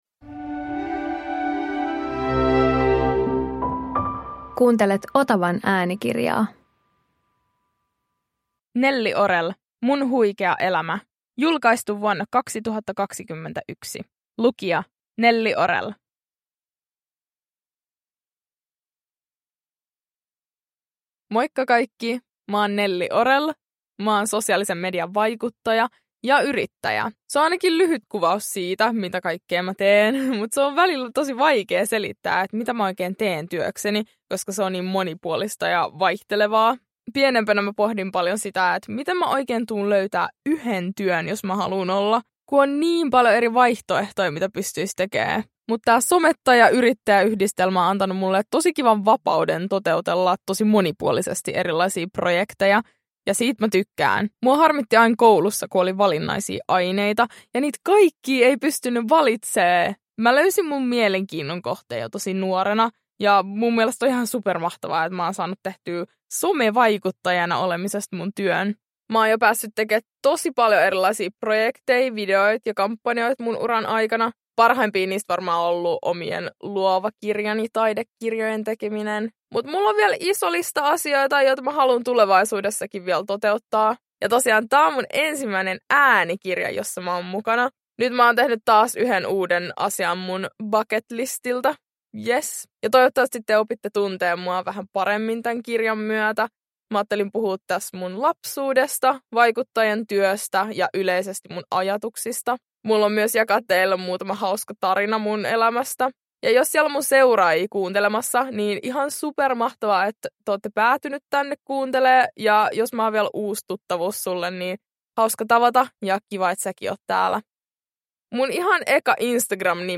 Äänikirjasarjassa tutut vaikuttajasuosikit kertovat itsestään kuin hyvälle kaverille.